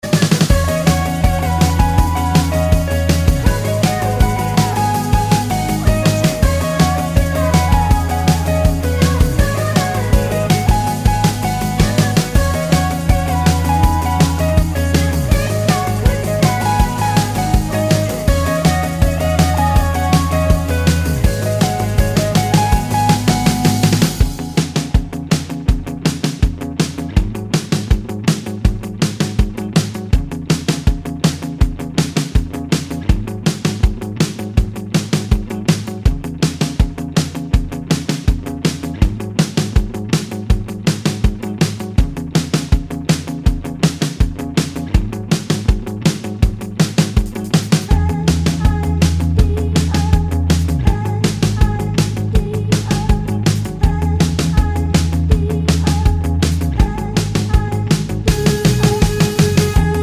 With Clean Backing Vocals Pop (2010s) 3:42 Buy £1.50